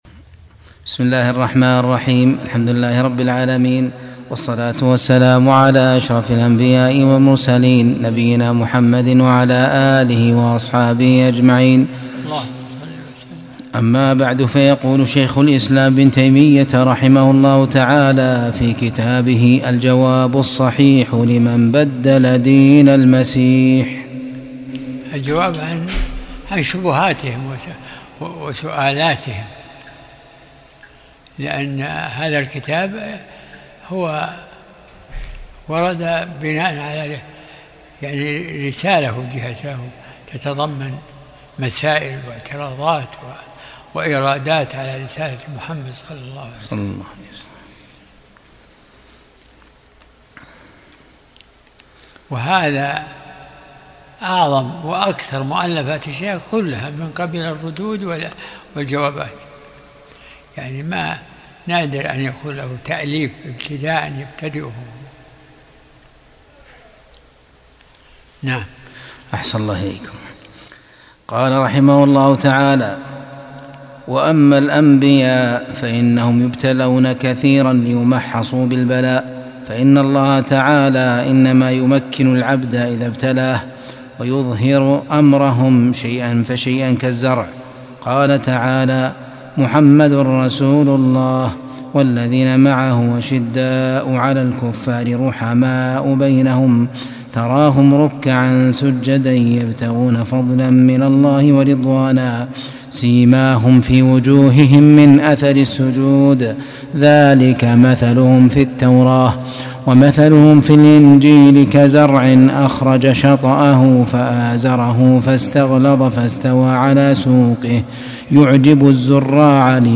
درس الأحد 36